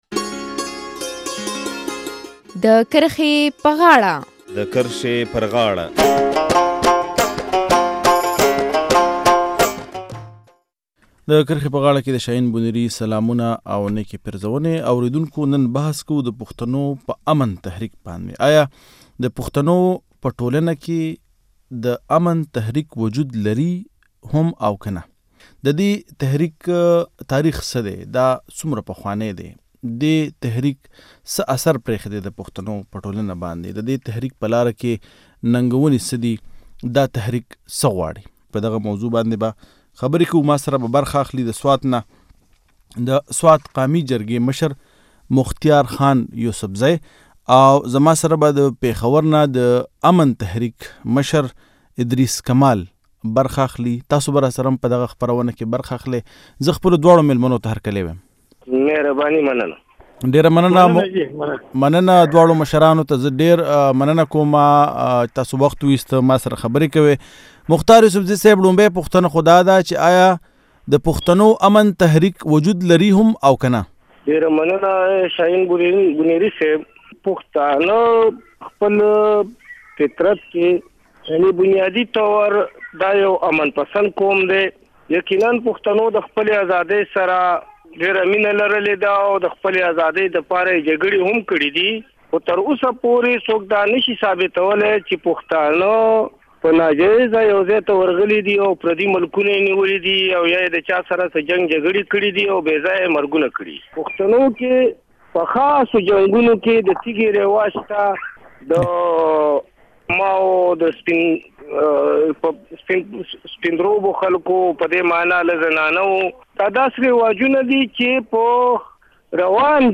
په پښتنو کې د امن تحریک په ضرورت بحث دلته واوری